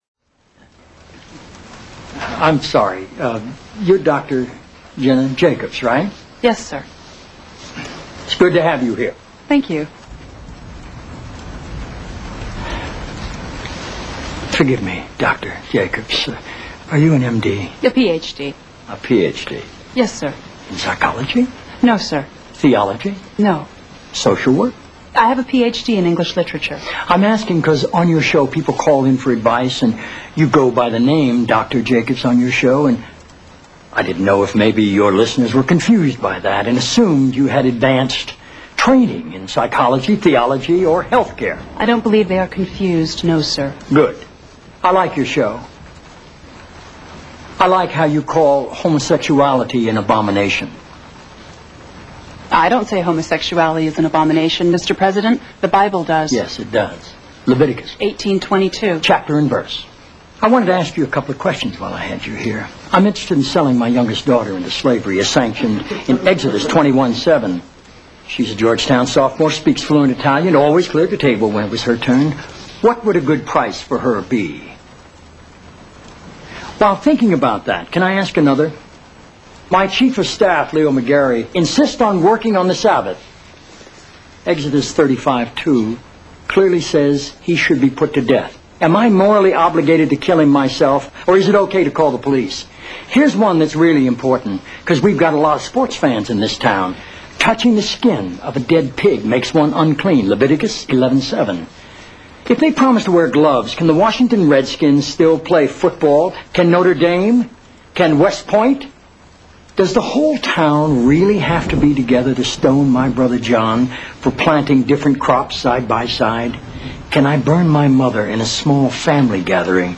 WMA Windows Media Audio stereo version of the West Wing with "President Bartlett" click